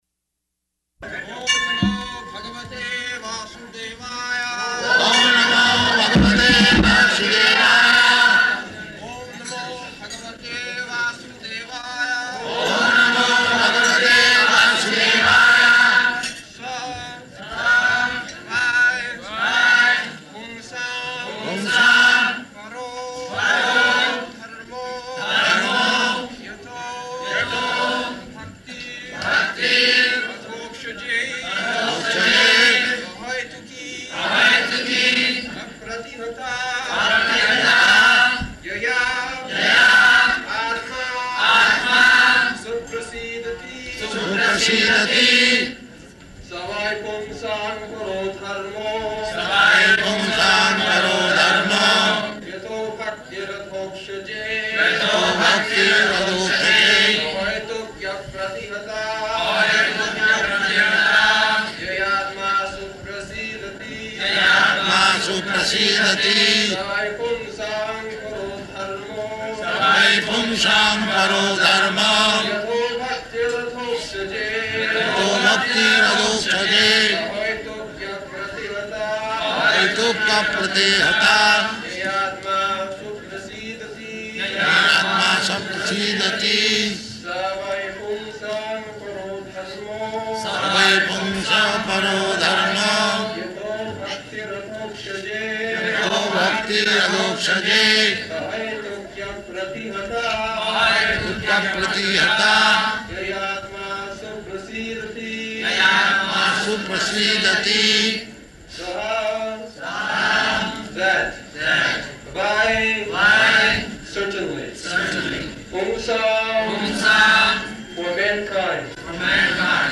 April 18th 1974 Location: Hyderabad Audio file
[Prabhupāda and devotees repeat]